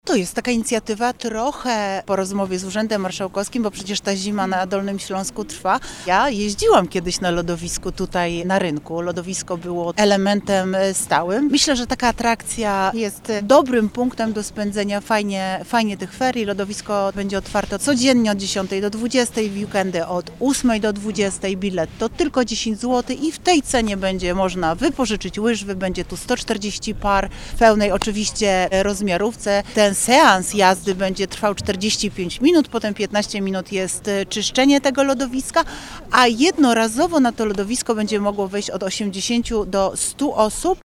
Na otwarcie już w poniedziałek o 14:00 zaprasza Renata Granowska, wiceprezydent Wrocławia.